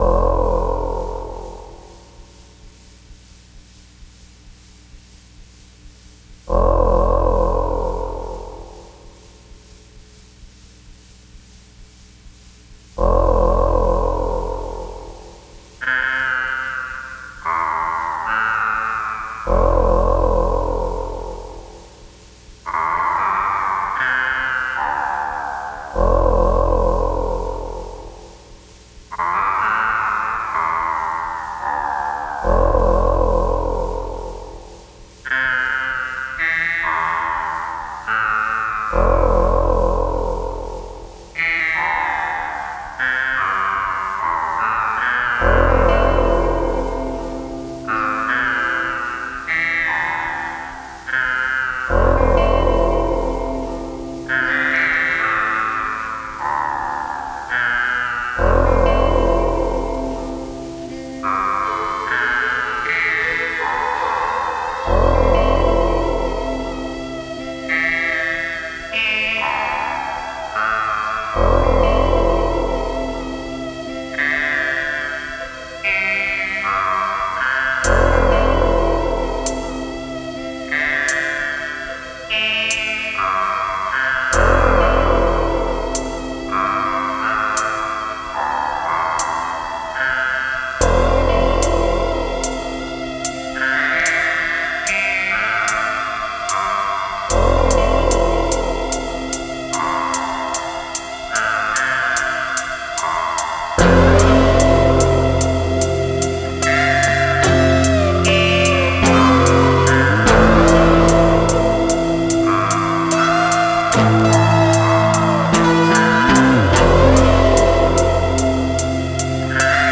Электроника